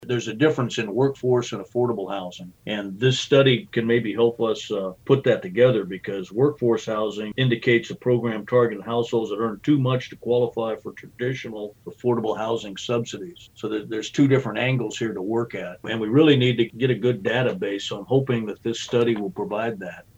Mayor Wynn Butler says the housing study will be a data point for the city to have an affordable housing group as well as a workforce housing group.